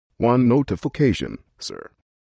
Звуки сообщений
На данной странице вы можете прослушать онлайн короткие звуки уведомлений для sms на  телефон android, iPhone и приложения.